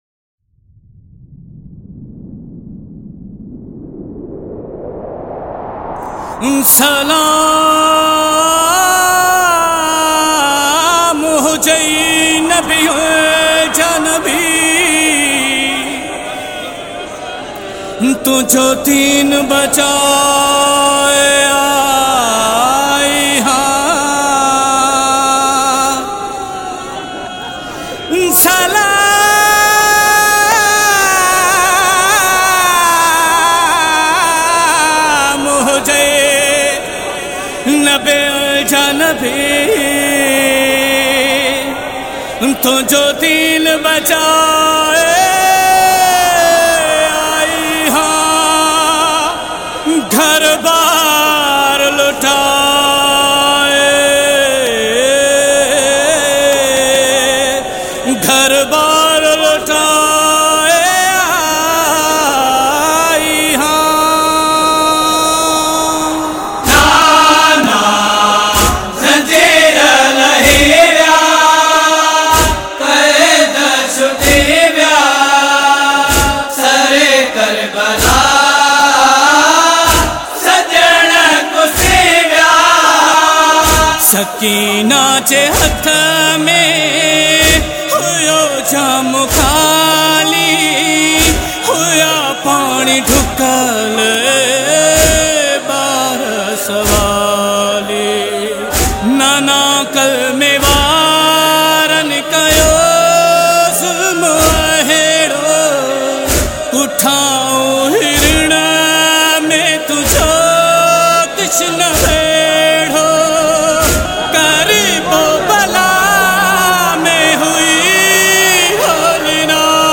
Sindhi Noha